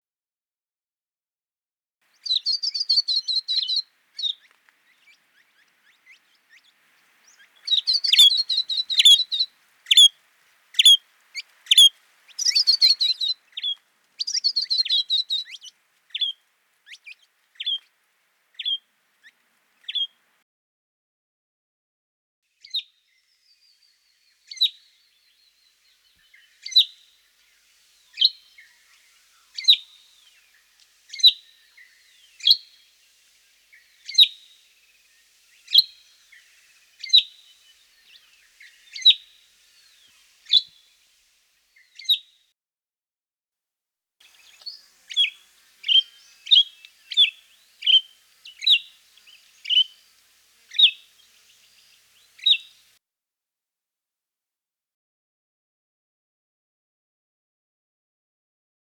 Unique Australian Bird Sounds
western spinebill
31-western-spinebill.mp3